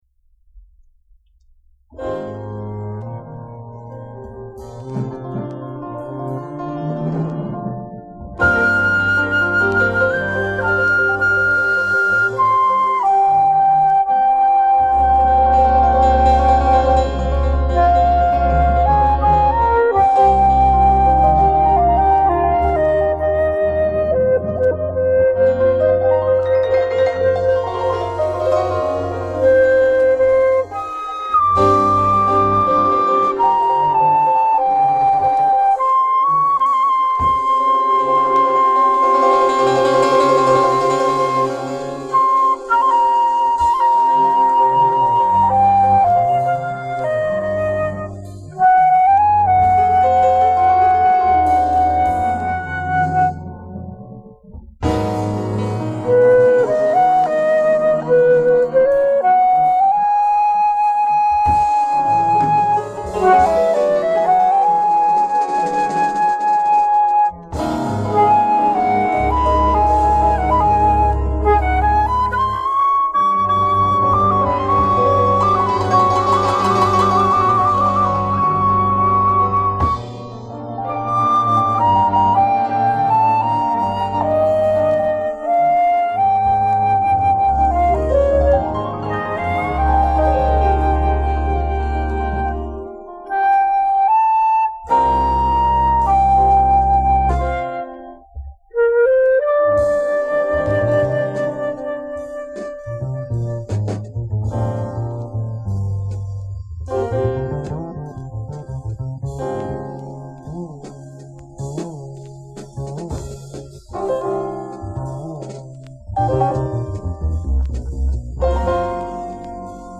alto saxophone/flute*
piano
bass
drums
Recorded: February at RG. Studio, Vicenza, Italy